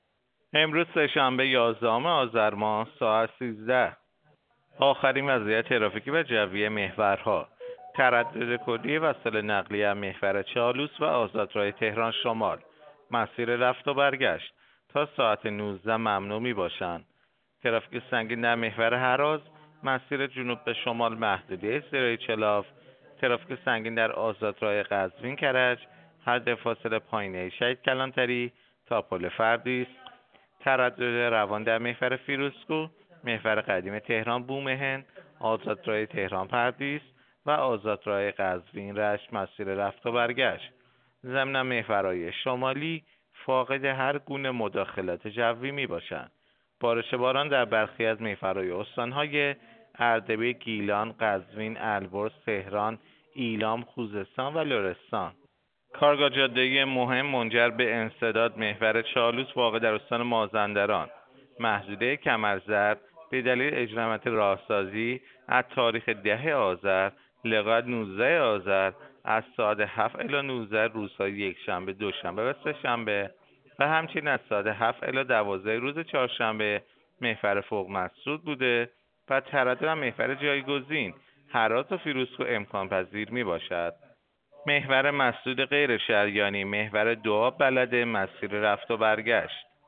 گزارش رادیو اینترنتی از آخرین وضعیت ترافیکی جاده‌ها ساعت ۱۳ یازدهم آذر؛